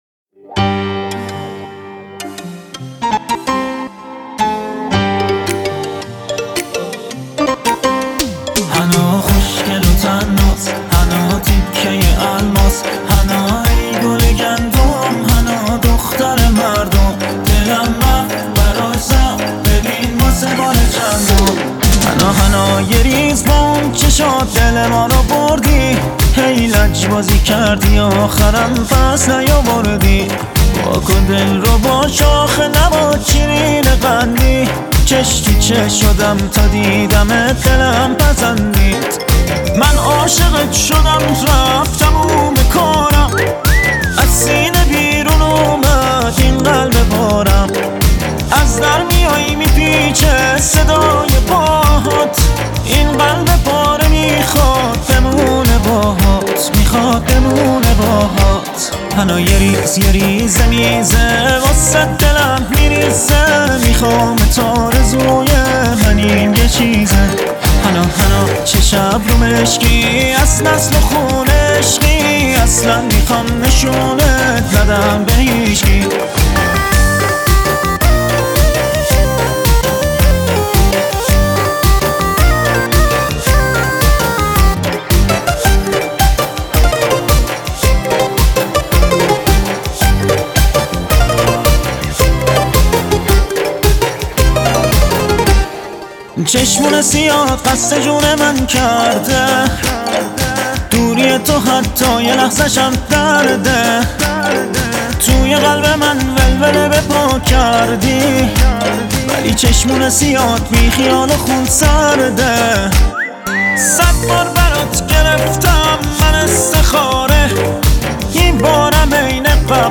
موسیقی پاپ است.